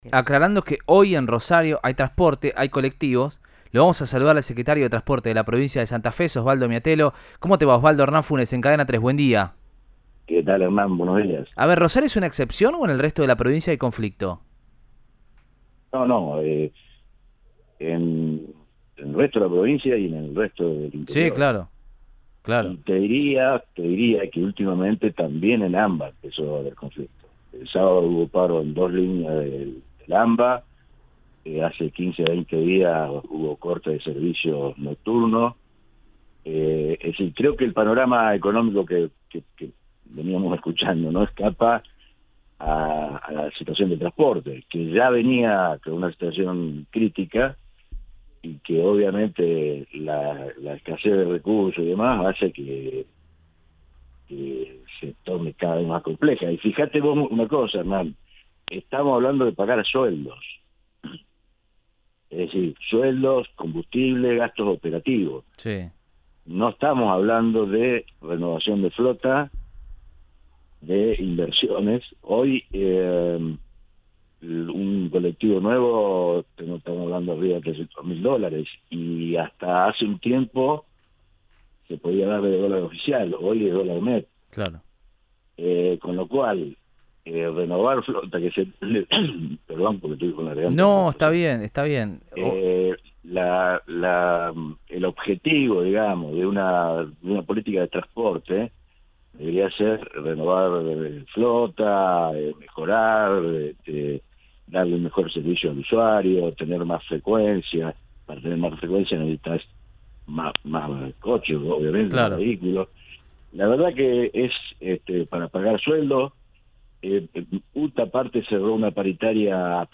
Rosario pudo sortear el paro de la UTA pero la situación del sector es preocupante. En Cadena 3 Rosario, Osvaldo Miatello, Secretario de Transporte de Santa Fe brindo detalles de la situación.